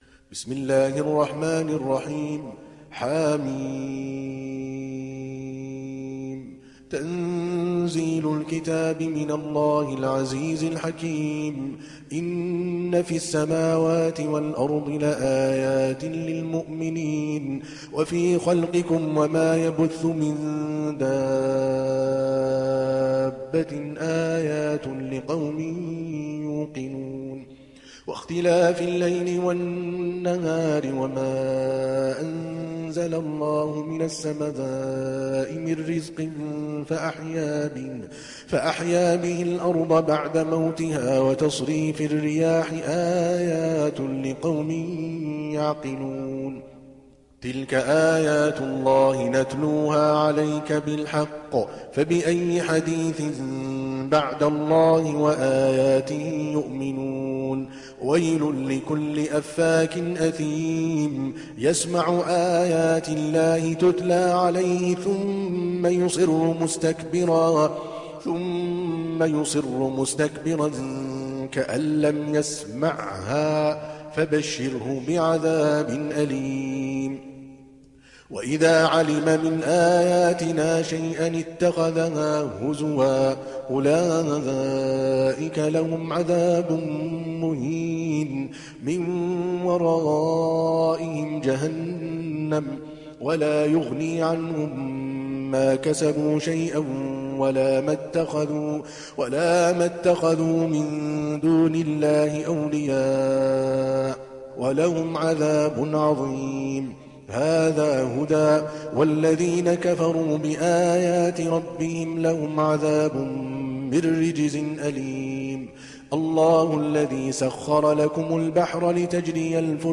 دانلود سوره الجاثيه mp3 عادل الكلباني روایت حفص از عاصم, قرآن را دانلود کنید و گوش کن mp3 ، لینک مستقیم کامل